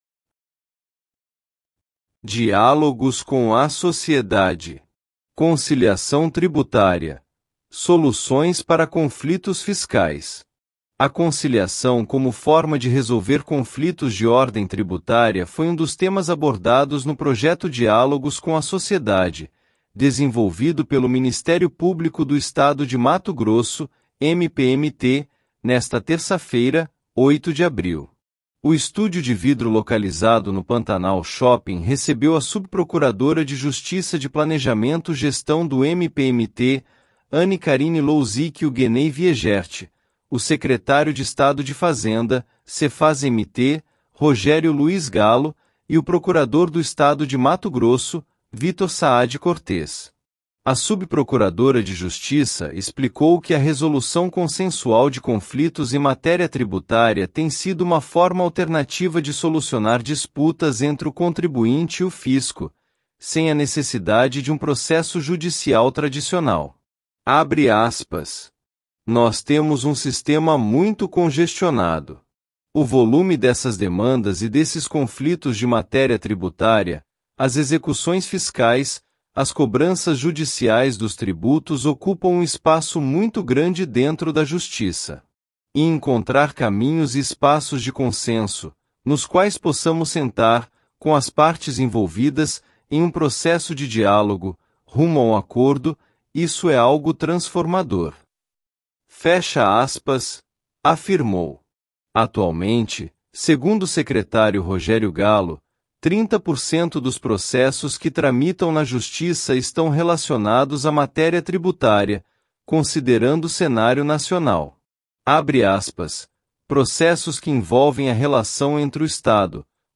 A conciliação como forma de resolver conflitos de ordem tributária foi um dos temas abordados no projeto Diálogos com a Sociedade, desenvolvido pelo Ministério Público do Estado de Mato Grosso (MPMT), nesta terça-feira (08/04). O estúdio de vidro localizado no Pantanal Shopping recebeu a subprocuradora de Justiça de Planejamento e Gestão do MPMT, Anne Karine Louzich Hugueney Wiegert, o secretário de Estado de Fazenda (Sefaz-MT), Rogério Luiz Gallo, e o procurador do Estado de Mato Grosso, Victor Saad Cortez.